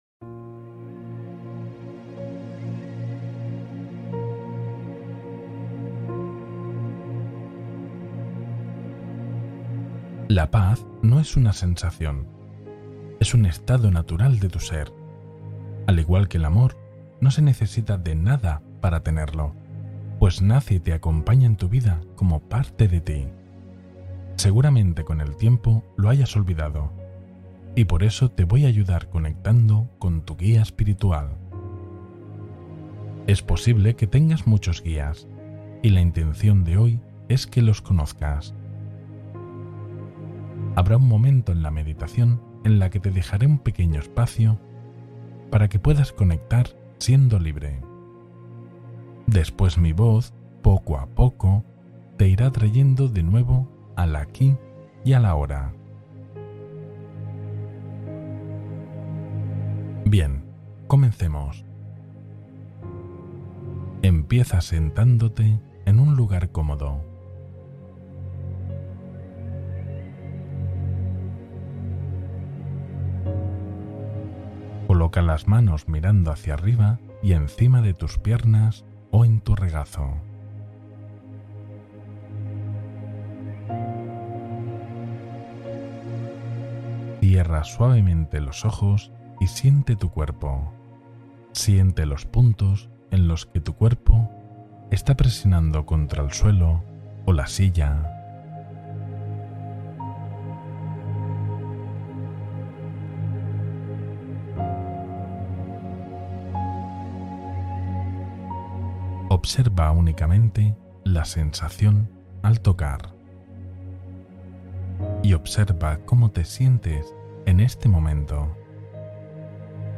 Canal Interno Abierto: Meditación Avanzada de Escucha Espiritual